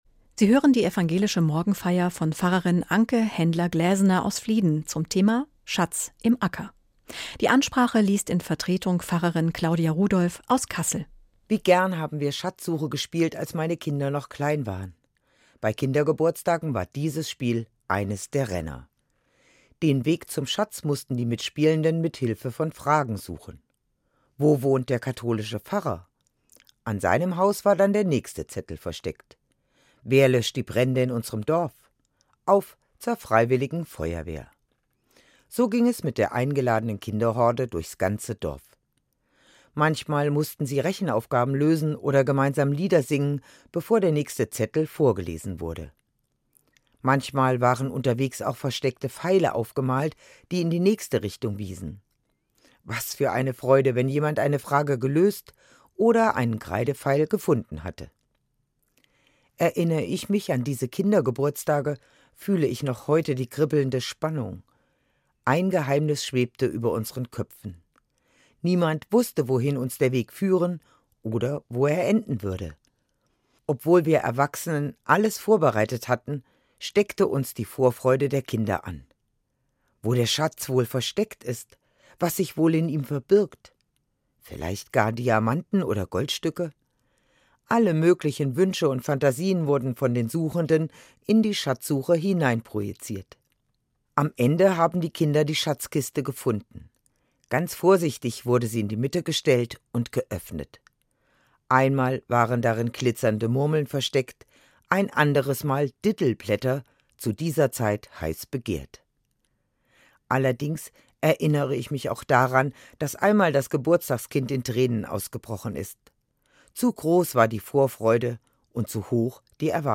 Eine Sendung